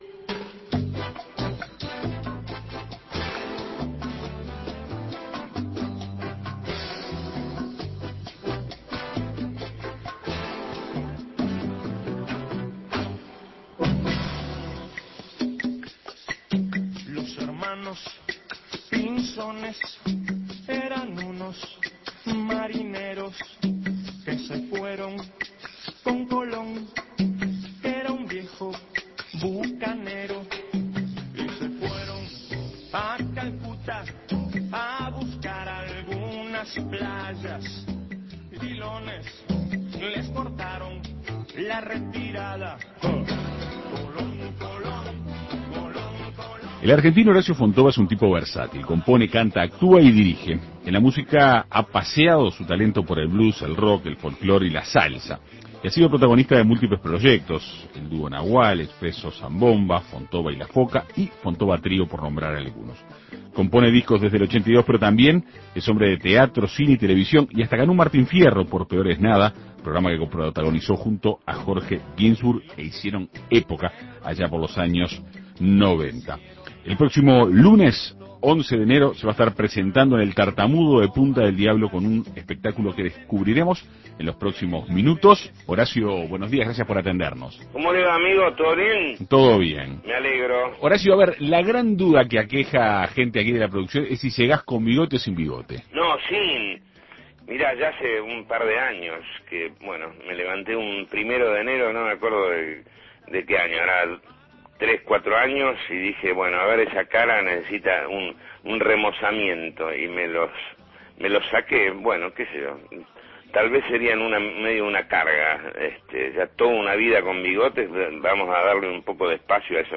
En Perspectiva Segunda Mañana dialogó con el artista sobre su presentación el próximo 11 de enero en el boliche El Tartamudo de Punta del Diablo.